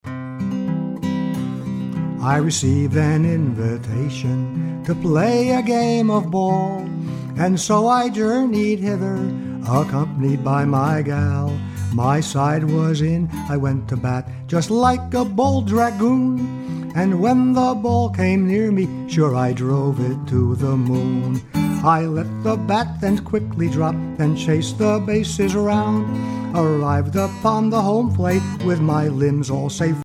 Voicing: PVG Collection